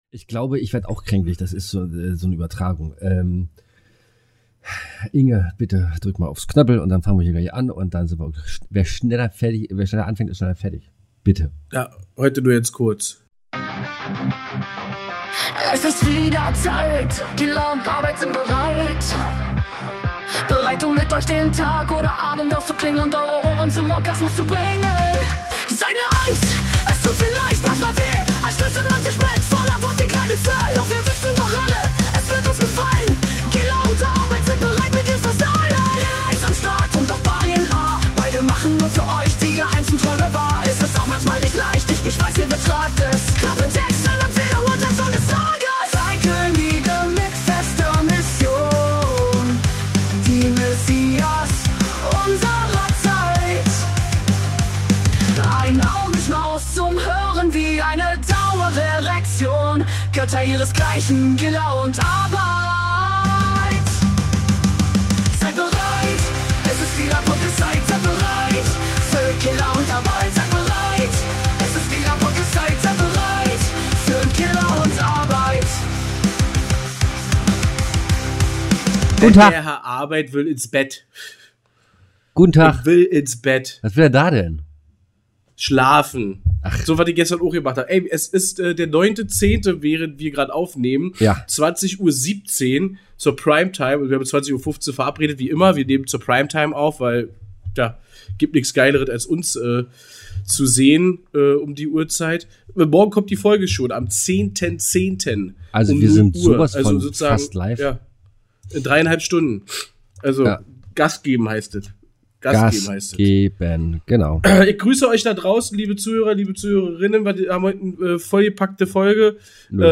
Die beiden Herren sprechen über den Charme des Winzer Fests, die atemberaubenden Lichter des „Festival of Lights“ in Berlin und den lang erwarteten Kinofilm „Joker 2“.